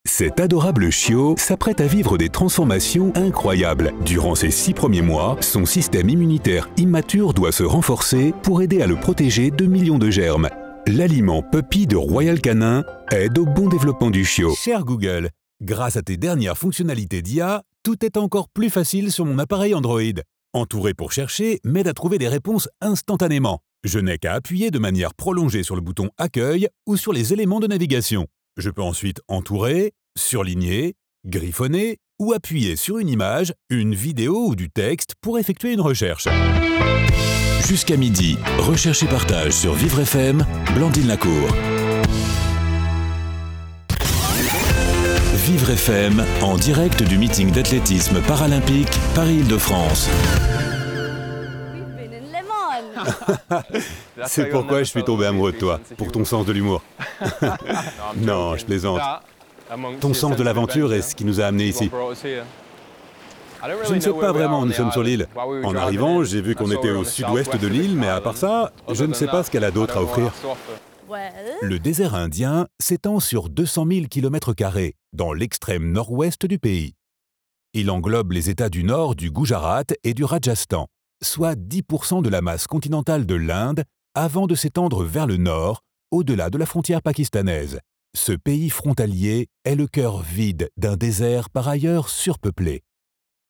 Voix off
30 - 50 ans - Basse